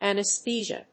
音節an・aes・the・sia 発音記号・読み方
/`ænəsθíːʒə(米国英語), ˈæn.əs.θiːz.i.ə(英国英語)/